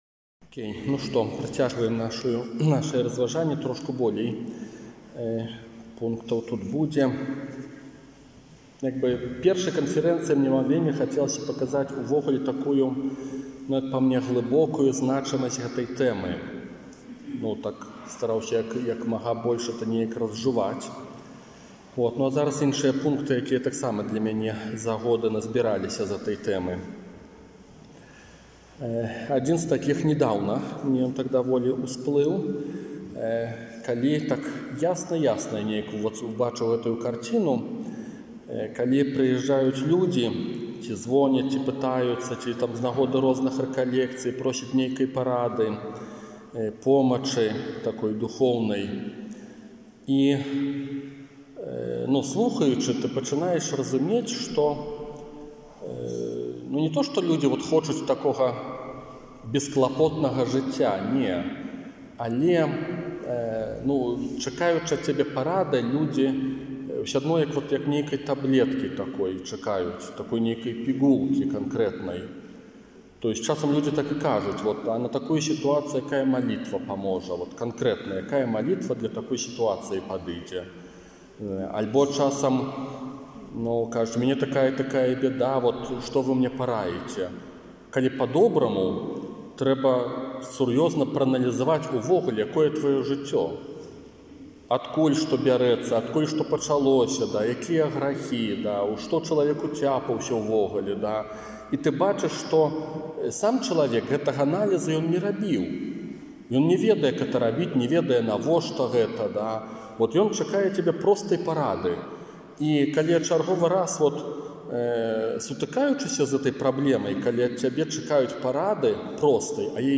Аўдыёзапіс двух канферэнцый, якія прагучалі падчас "Вінаградніку" ў Оршы 14 студзеня 2023 года